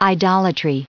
added pronounciation and merriam webster audio
1488_idolatry.ogg